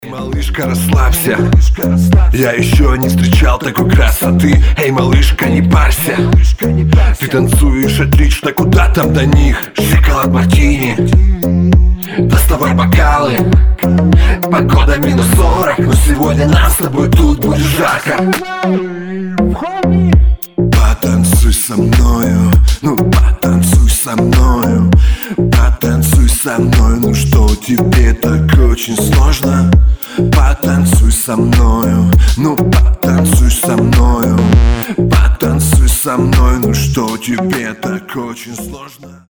• Качество: 320, Stereo
ритмичные
мужской вокал
Хип-хоп
dance
чувственные
качающие
Bass